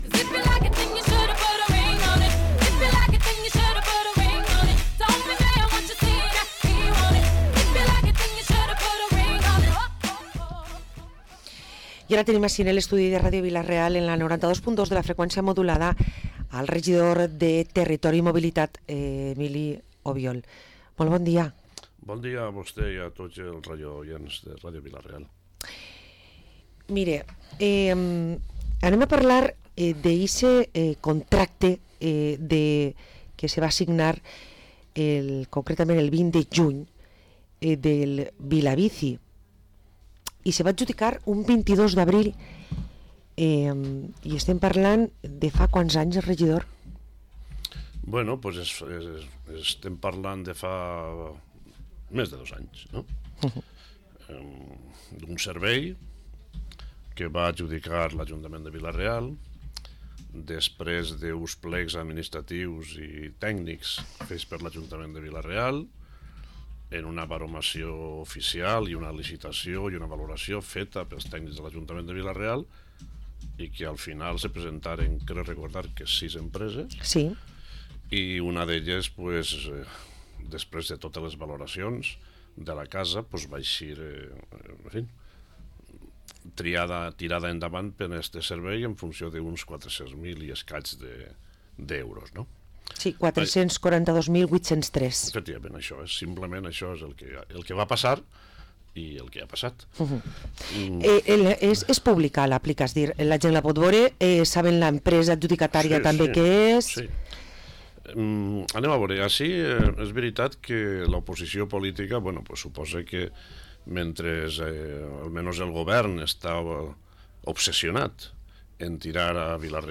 Parlem amb Emili Obiol, regidor de mobilitat i territori a l´Ajuntament de Vila-real